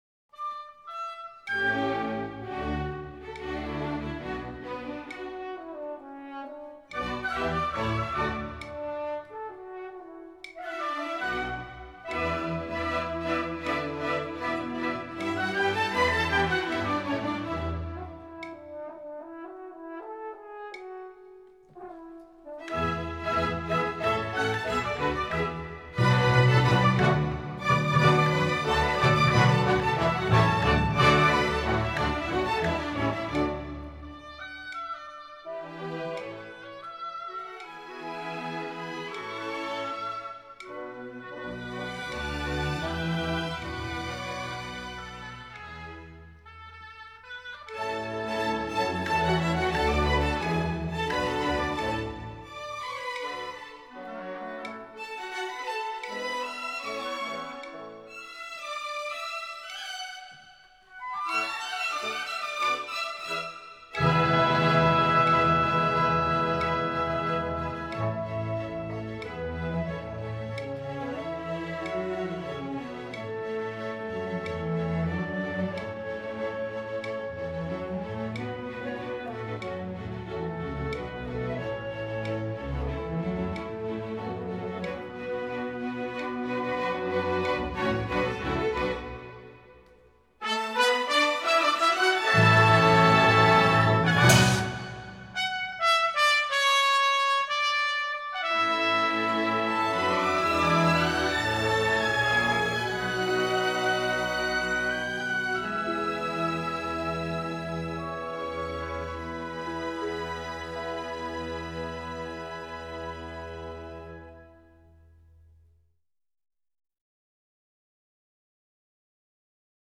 全部曲目充分结合了管弦乐器和民族乐器，使得音乐本身即更加立体感，又不失民族本色